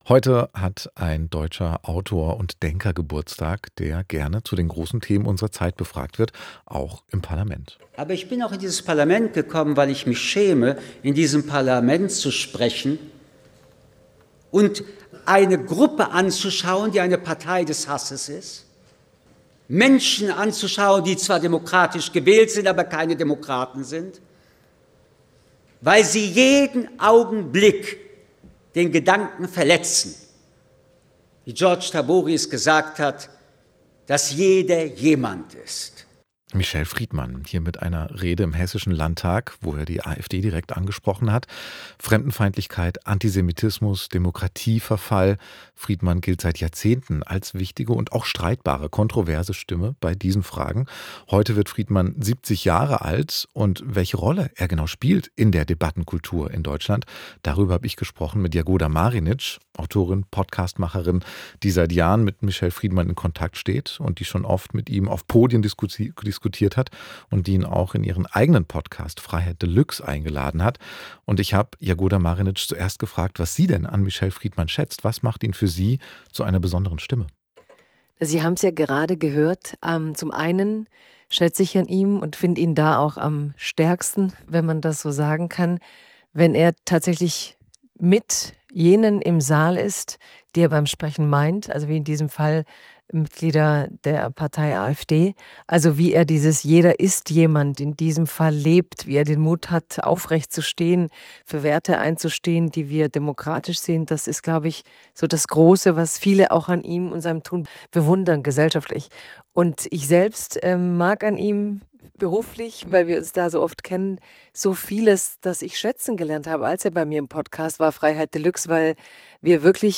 Ein Gespräch mit Jagoda Marinić, Schriftstellerin und Podcasterin
Über die Bedeutung von Michel Friedman für die politische Öffentlichkeit sprechen wir auf radio3 mit der Schriftstellerin Jagoda Marinić, die mit ihm in Gesprächsreihen und Bühnenformaten regelmäßig zusammenarbeitet.